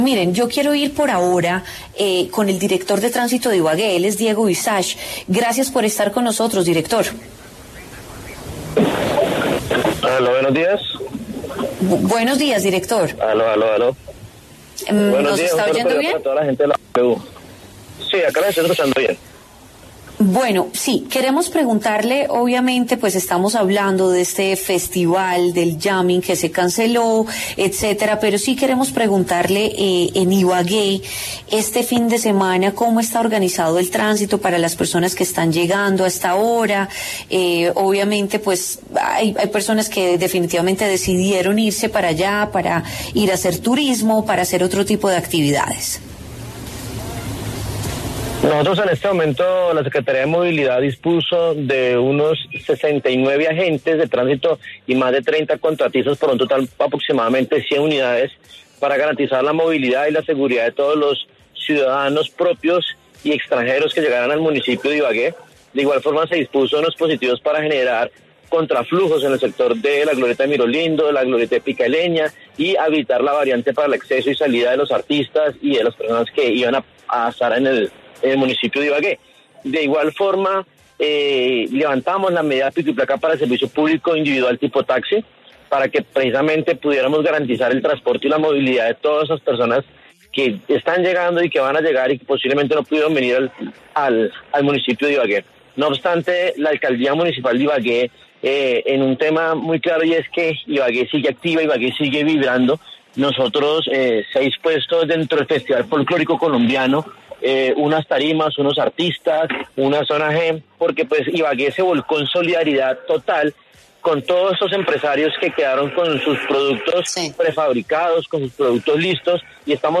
“Se amplio el horario de las discotecas hasta las cinco de la mañana” informó Diego Visash en W Fin de Semana.